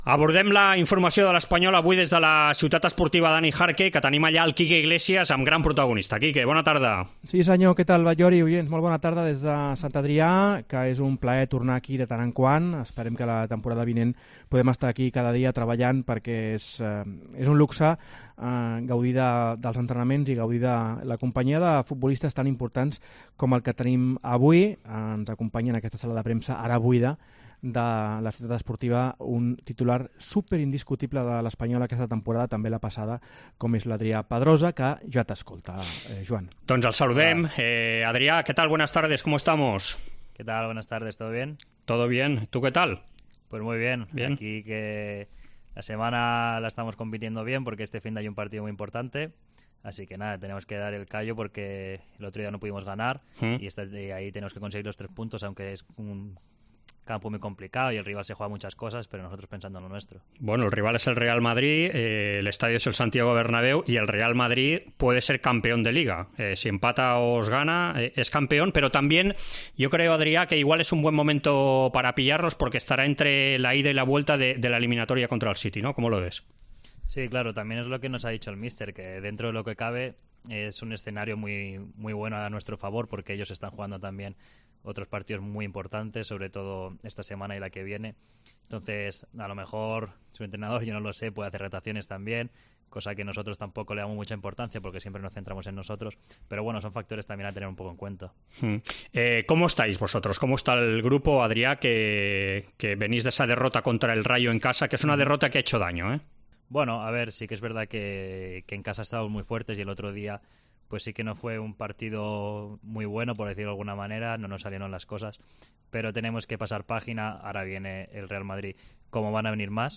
El jugador del RCD Espanyol Adrià Pedrosa ha atendido a los micrófonos de Esports COPE en la Ciudad Deportiva Dani Jarque a pocos días para la visita del Real Madrid este sábado en Cornellà-El Prat (16:15 horas) y con el club decidiendo el proyecto de futuro para la próxima temporada.